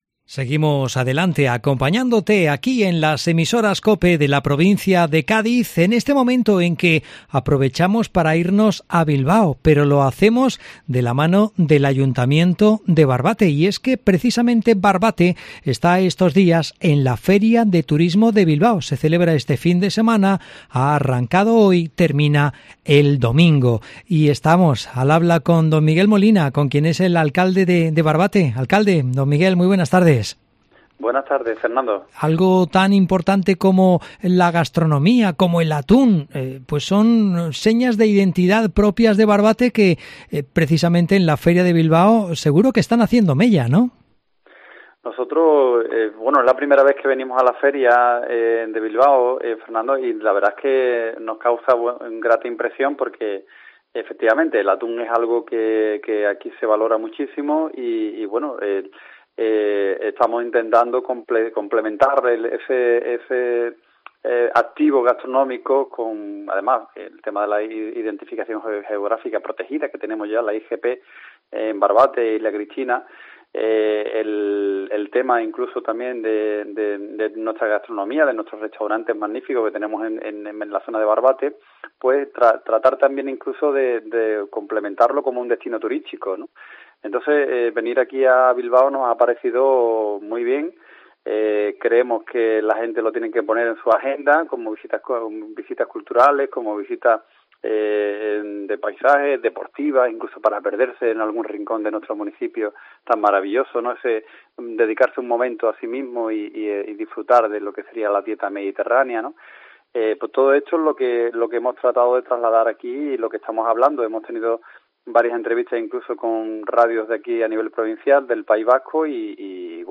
Entrevista a Miguel Molina, alcalde de Barbate
AUDIO: Escucha la entrevista al alcalde de Barbate Miguel Molina